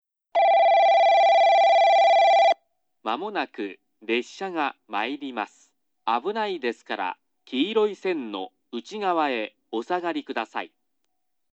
上下接近放送　男声 放送は九州カンノ型Cです。上下とも男声放送で、放送は1回のみです。
スピーカーは元々カンノボックス型が設置されており、その後ソノコラム（ミニ）に取り換えられていましたが、さらに放送更新に伴ってか、新たにソノコラムに取り換えられています。（設置位置も従来とは若干異なる場所）